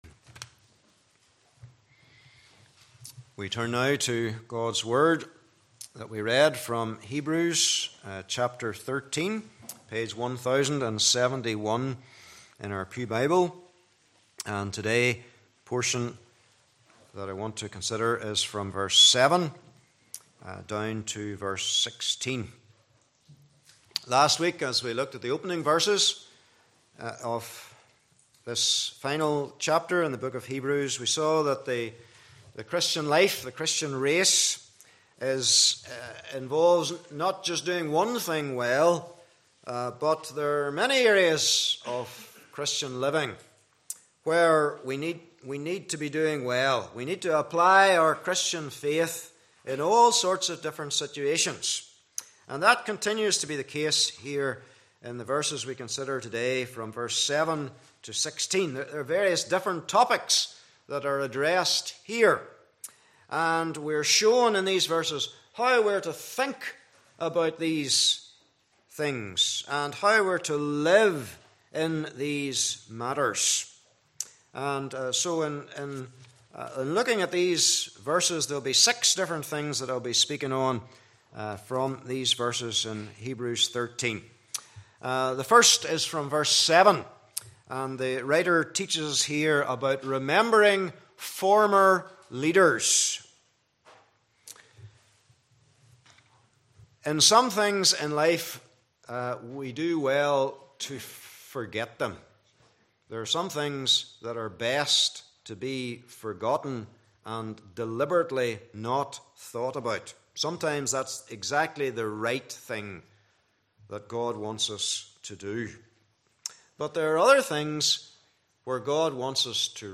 Service Type: Morning Service